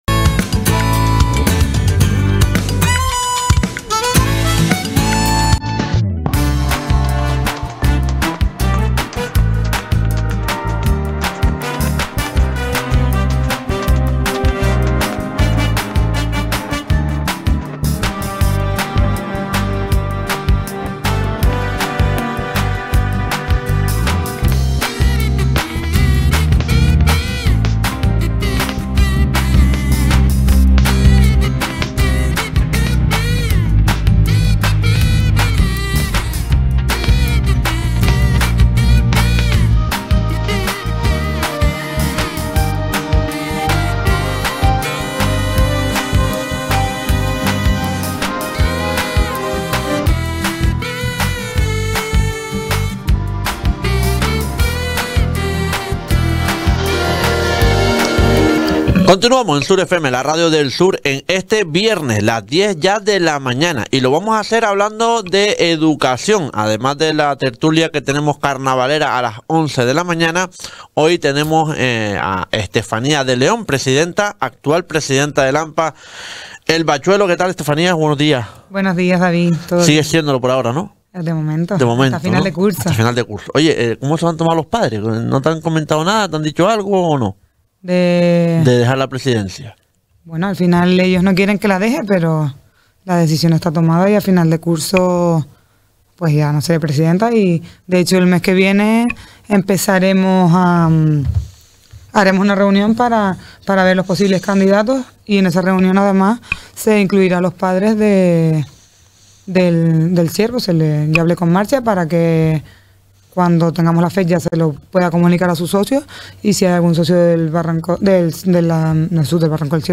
El Sur Despierta Entrevista